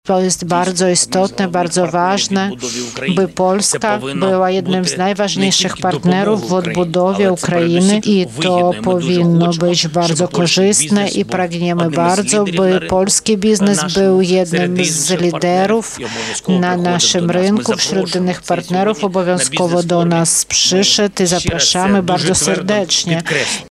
Wołodymyr Zełensk– mówi Wołodymyr Zełenski, prezydent Ukrainy.